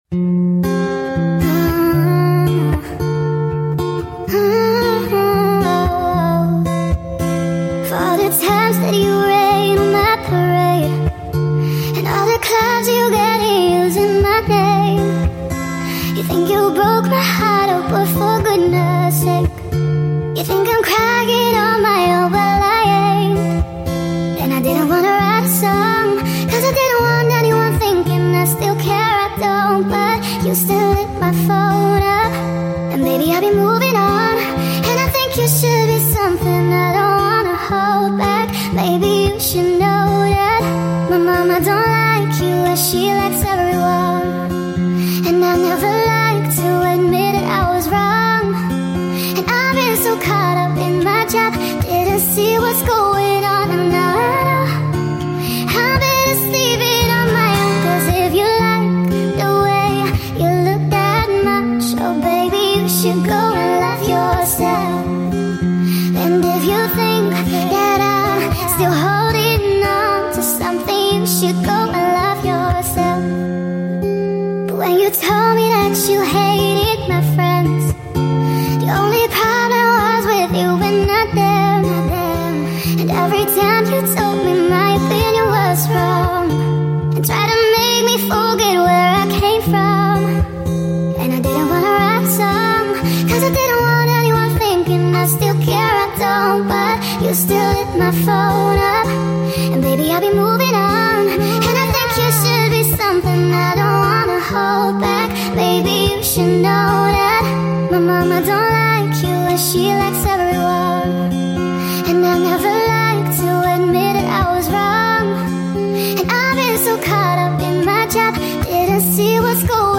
Nightcore
Cover